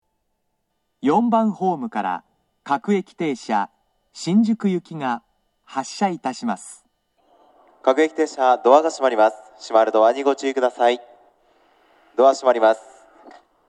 2010年11月より接近放送の冒頭に、『いきものがかり』の楽曲、「YELL」が接近メロディーとして流れています。
（男性）
接近放送
各駅停車　新宿行（8両編成）の接近放送です。